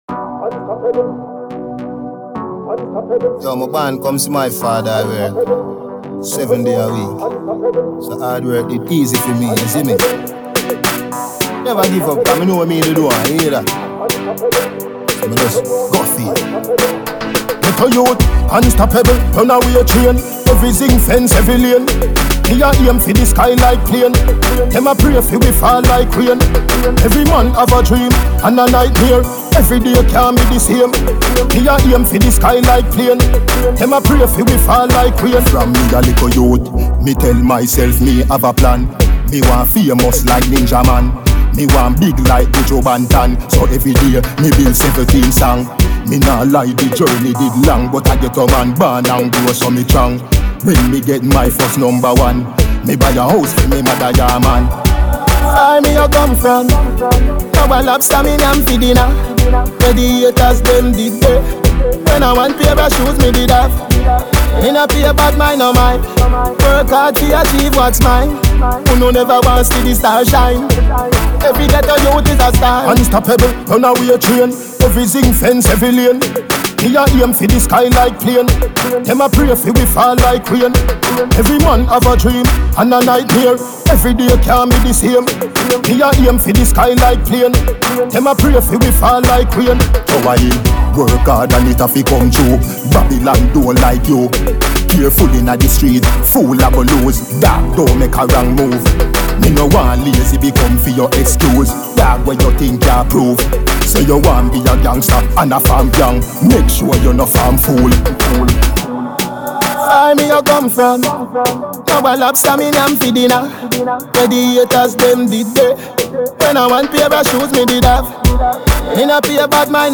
AlbumDancehall Riddim